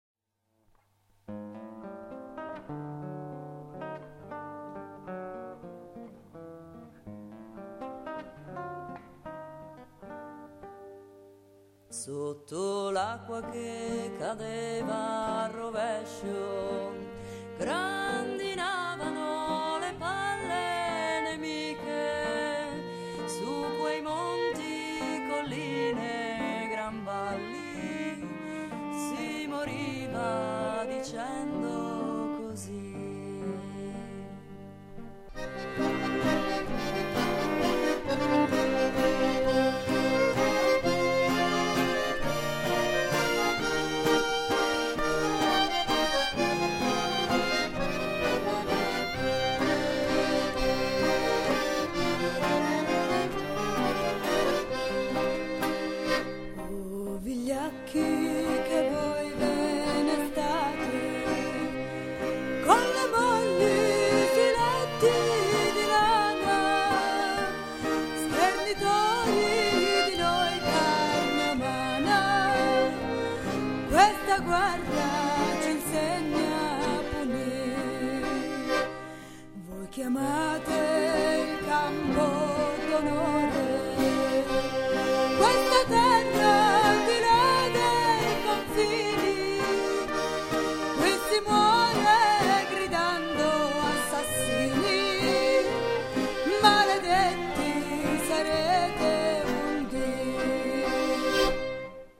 prove aperte dirette da
Voce
Chitarra
Tromba
Violino
Fisarmonica